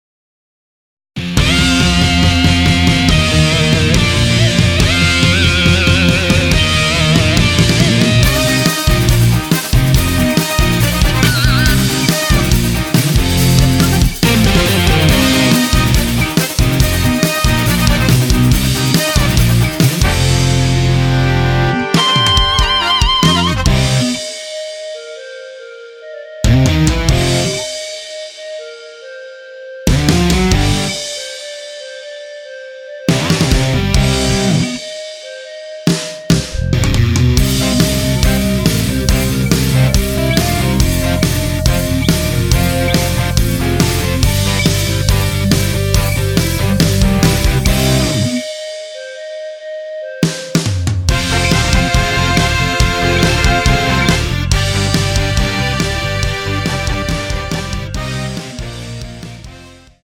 원키에서(-8)내린 멜로디 포함된 MR입니다.
앞부분30초, 뒷부분30초씩 편집해서 올려 드리고 있습니다.
중간에 음이 끈어지고 다시 나오는 이유는